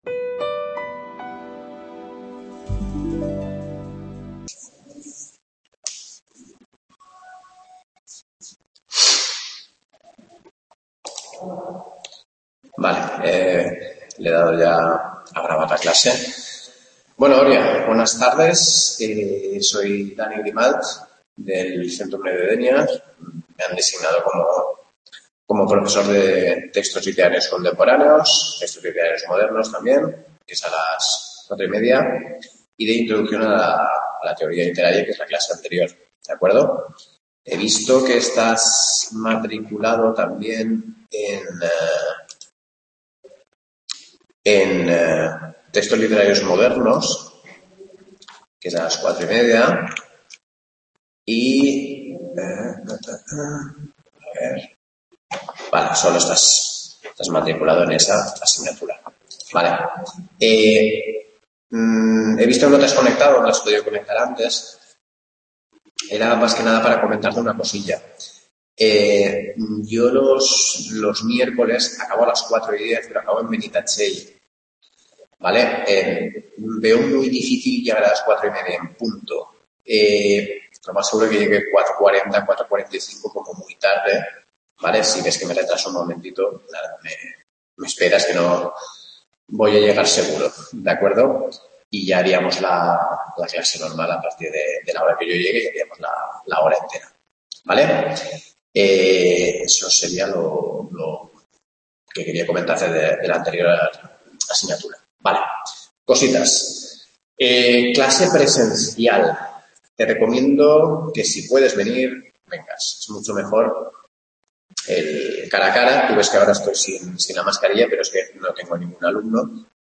CLASE 1 PRESENTACIÓN